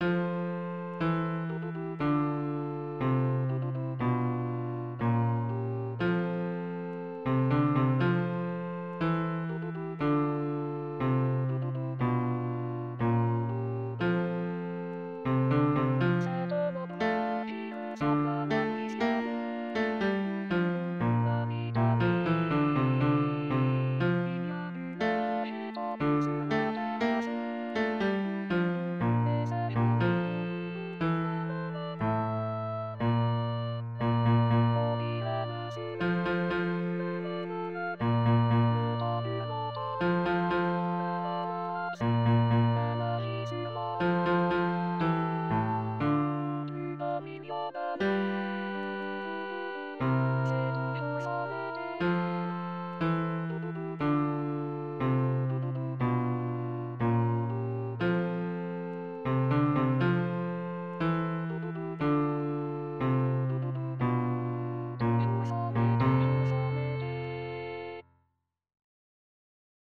Voix au piano et les autres en sourdine chantées